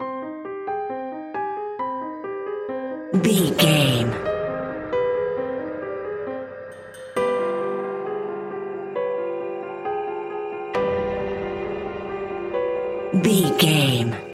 Aeolian/Minor
dark
eerie
haunting
ominous
suspense
piano
synthesiser